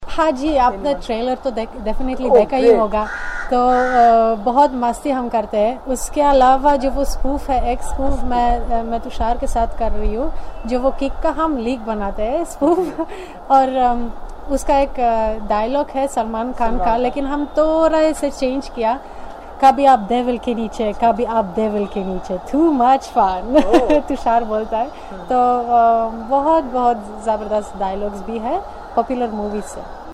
क्लॉडिया की जुबान से पोलिश एक्सेंट के साथ ह‌िंदी सुनना काफी दिलचस्प है .